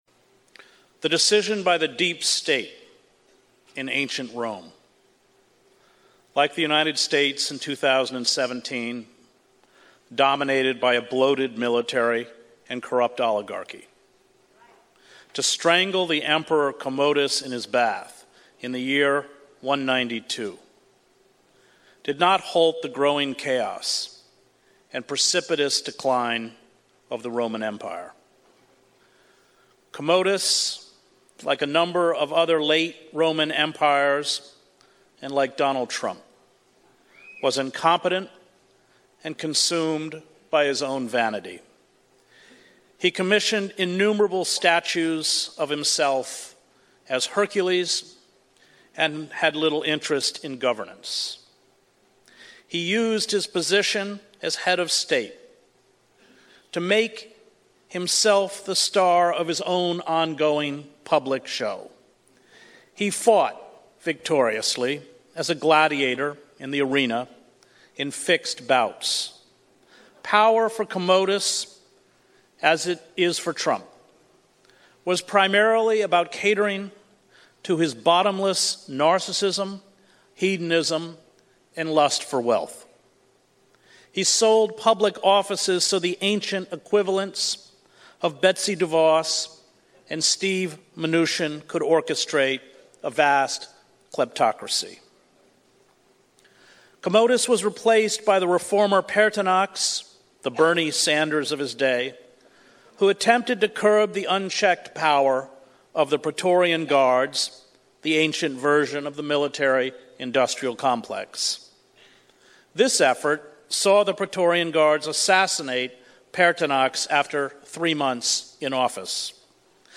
EP 52- Chris Hedges Amazing Speech In 2017 ***MUST LISTEN*** Collapse of the Roman Empire and the Decline of our current day American Empire